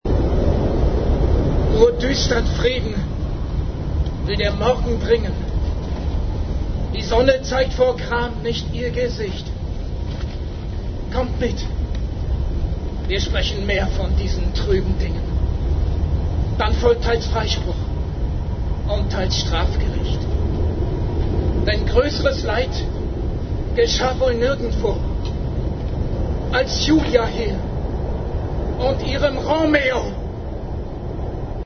Manchmal zitterten die Hände und eine Unruhe wich nicht aus Körper und Stimme.